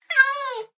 meow1.ogg